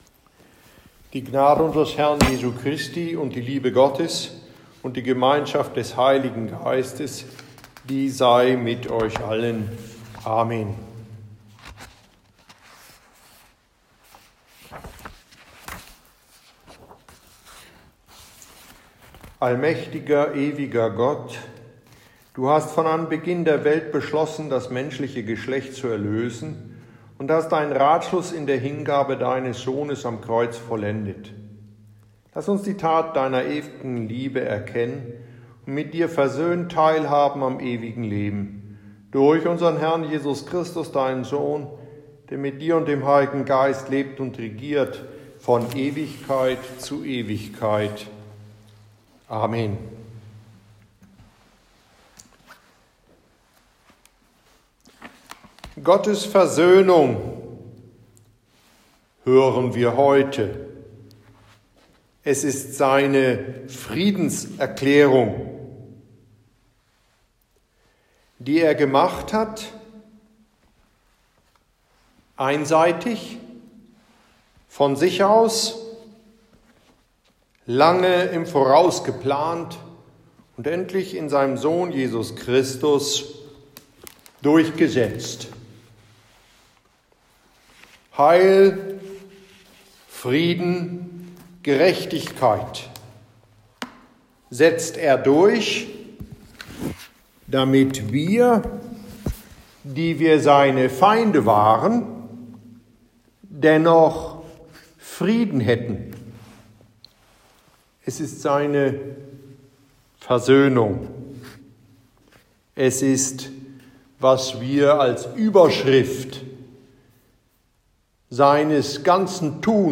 No organ. No bells, no colorful vestments either.
God willing, we will be singing these hymns a capella: